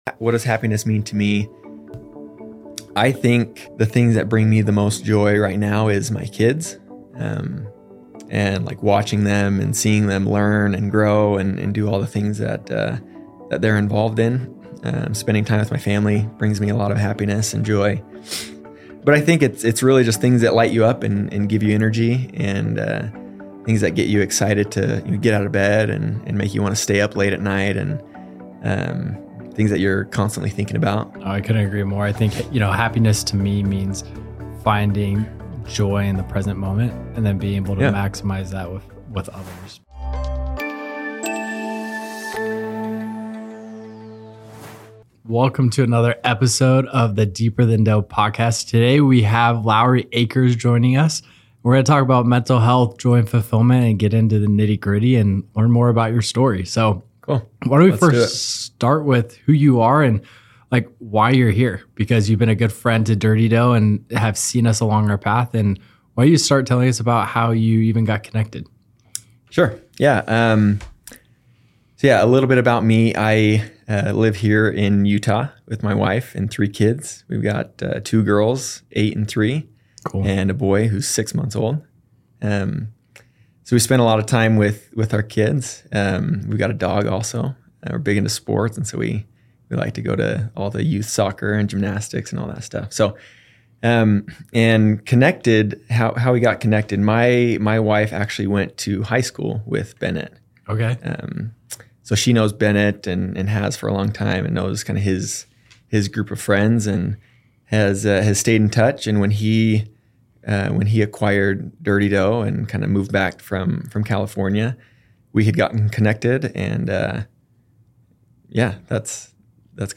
Discover insightful discussions and personal anecdotes that will inspire you to find happiness in both your personal life and your entrepreneurial journey. Don't miss this engaging conversation that goes beyond the surface and explores the deeper aspects of life and business.